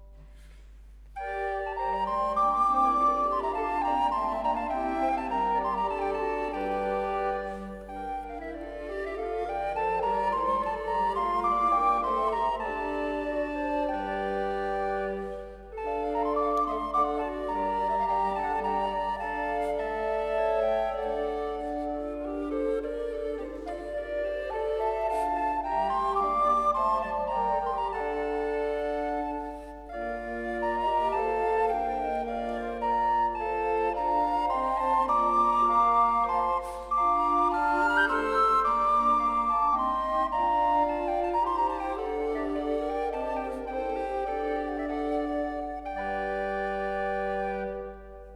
Genre : Consort de Flûtes à Bec.